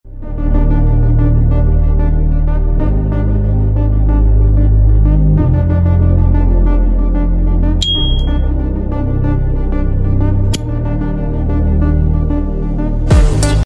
S.T. Dupont Sơn Mài Xanh Navy Nắp Kim Cương Mạ Vàng Sang Trọng Nhưng Vẫn Sở Hữu Âm Thanh Mở Nắp Cực Vang